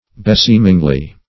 Definition of beseemingly.
[Archaic] -- Be*seem"ing*ly , adv.